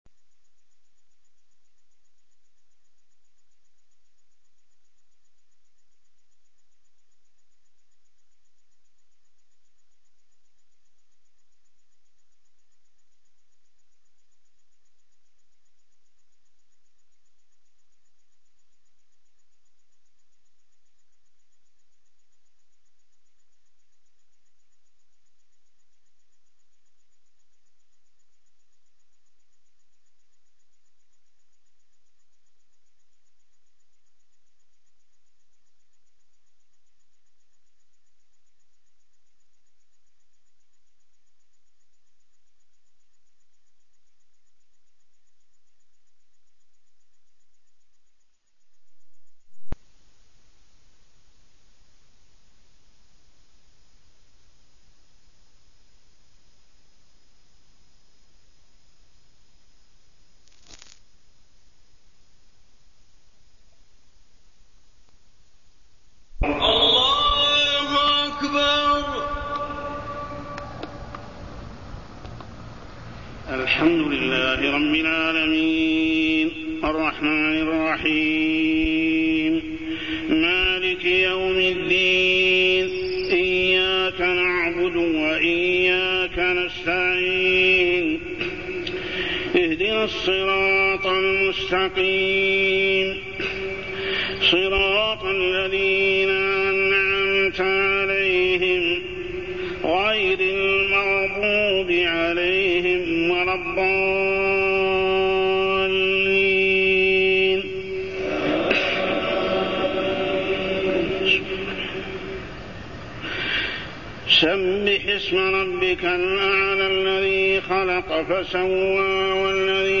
تاريخ النشر ١٦ محرم ١٤٢٤ هـ المكان: المسجد الحرام الشيخ: محمد بن عبد الله السبيل محمد بن عبد الله السبيل التوبة إلى الله